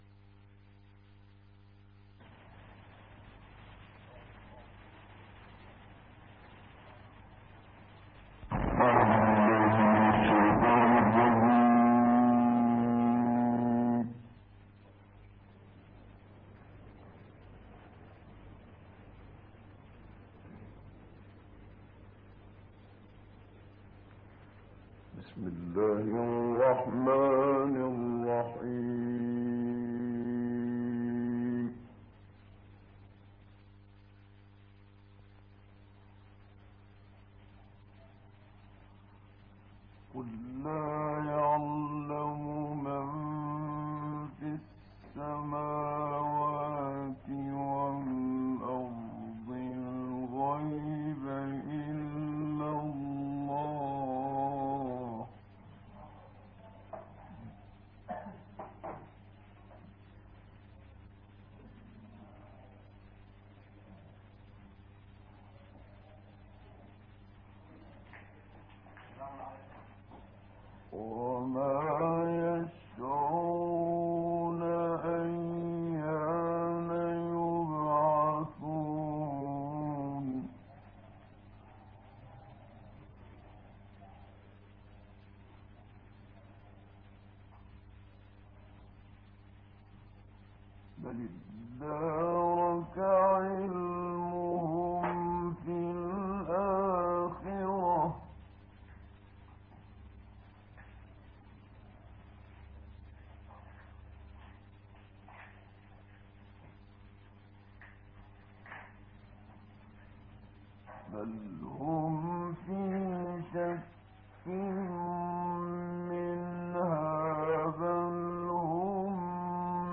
عنوان المادة 027 النمل 65-93 تلاوات نادرة بصوت الشيخ محمد صديق المنشاوي تاريخ التحميل السبت 11 نوفمبر 2023 مـ حجم المادة غير معروف عدد الزيارات 228 زيارة عدد مرات الحفظ 104 مرة إستماع المادة حفظ المادة اضف تعليقك أرسل لصديق